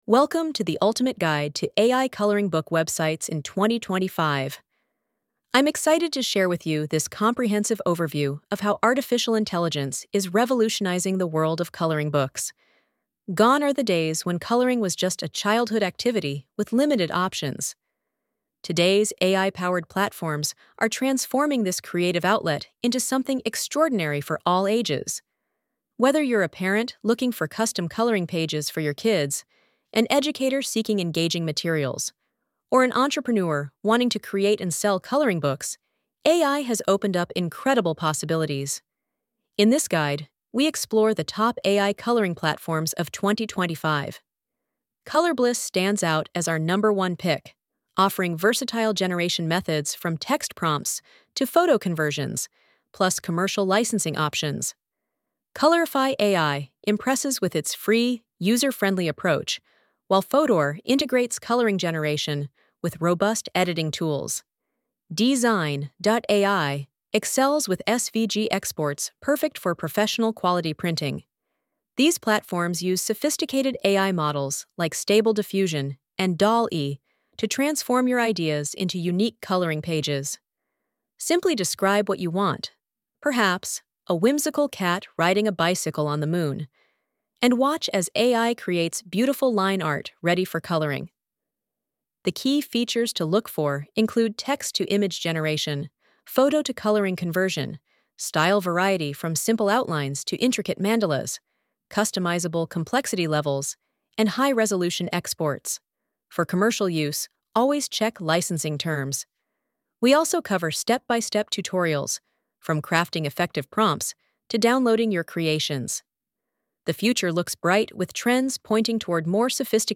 Duration: ~2.5 minutes • Professional AI-generated narration